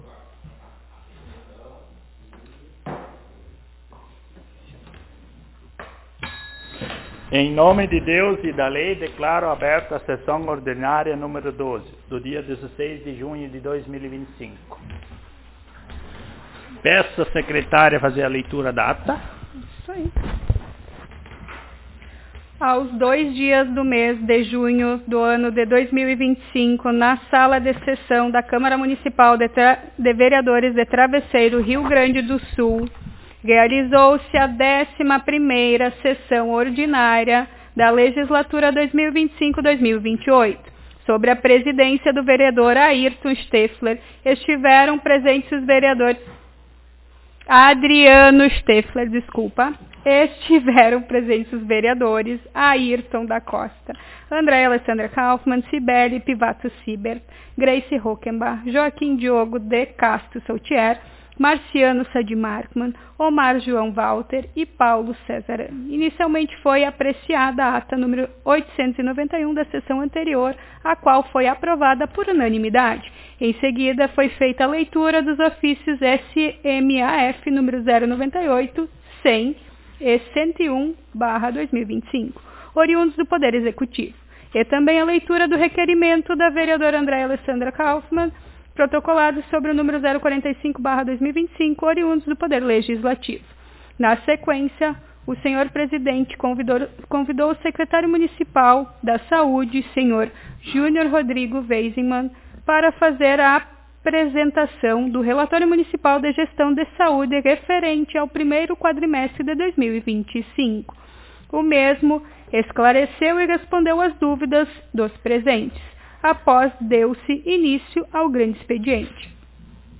Aos 16 (dezesseis) dias do mês de junho do ano de 2025 (dois mil e vinte e cinco), na Sala de Sessões da Câmara Municipal de Vereadores de Travesseiro/RS, realizou-se a Décima Segunda Sessão Ordinária da Legislatura 2025-2028.